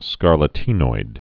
(skärlə-tēnoid)